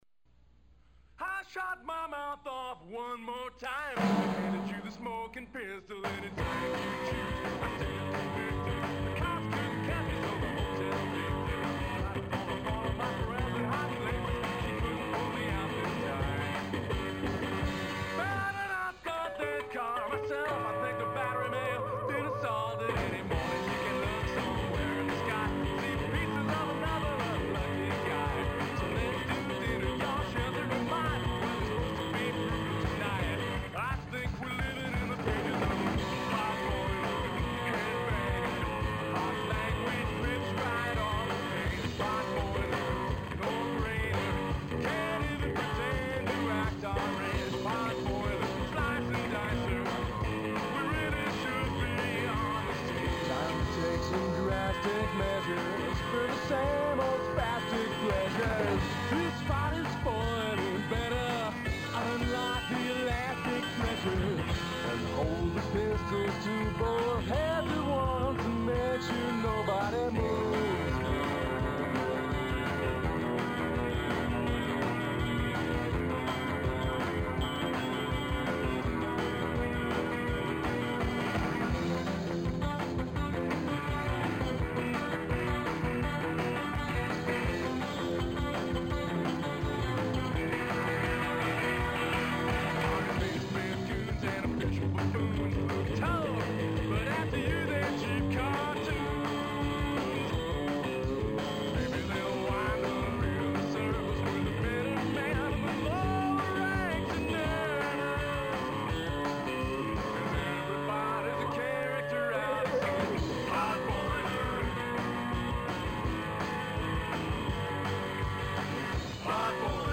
one of the older and punkier ones.